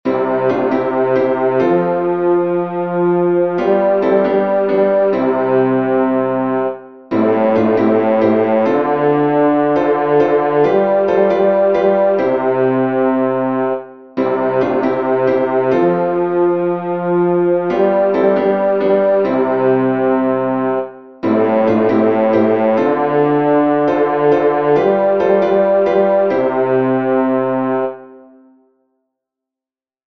Bass
open_wide_the_doors-bass.mp3